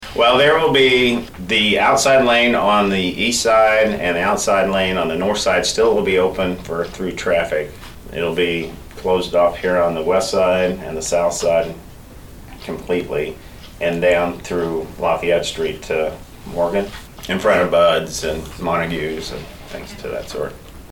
was at the meeting of the Saline County Commission on Thursday, June 9, and talked about some of the logistics involving street closures and parking.